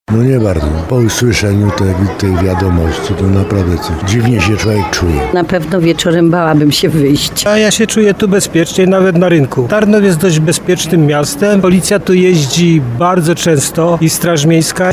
14nozownik-sonda.mp3